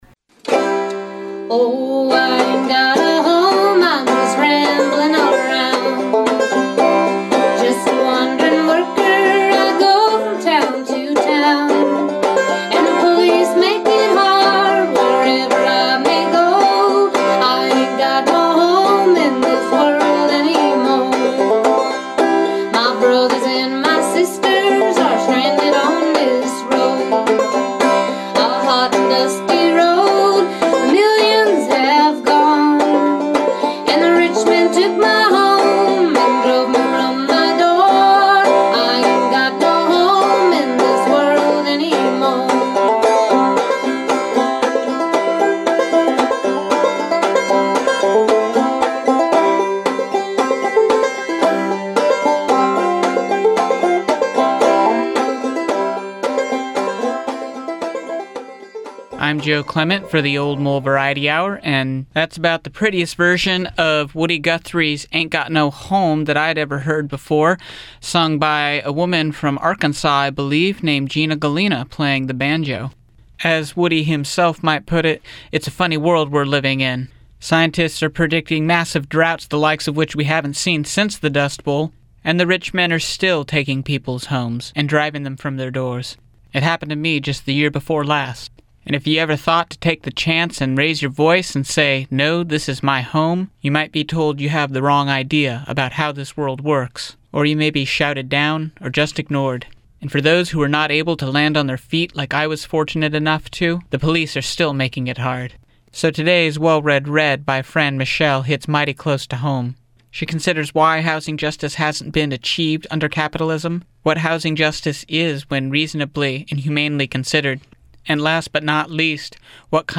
offers a set of short readings* on housing as a human right, the contradictions of human need vs. profit, and the success of direct collective action by tenants in establishing housing as a truly public good.
Begining with a musical clip